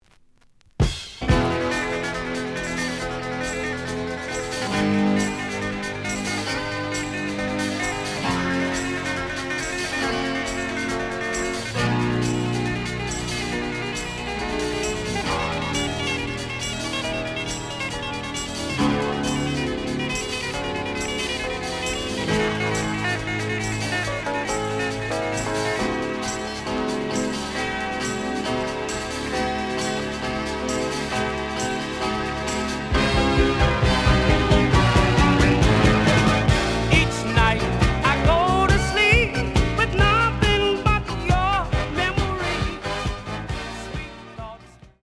●Genre: Disco
Slight edge warp.